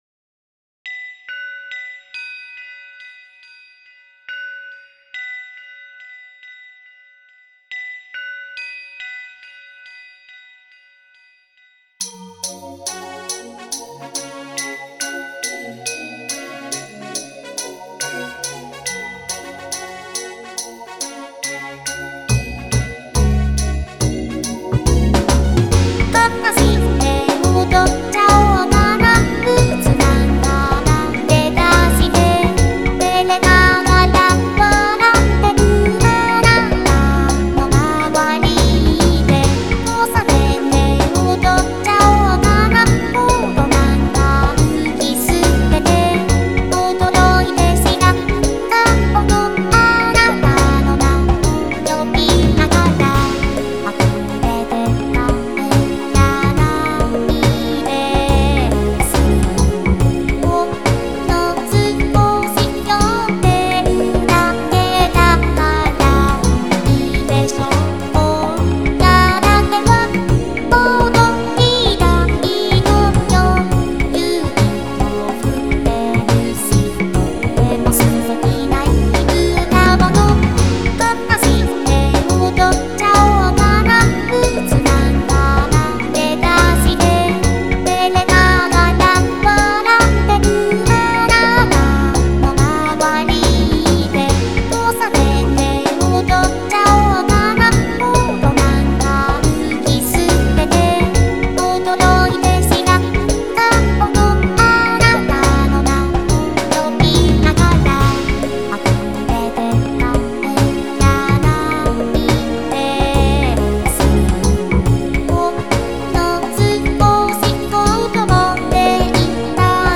とても素敵なイブだもの 作曲当初から厚意により歌詞を付けて頂いていたが、2007年、初音ミクが歌う。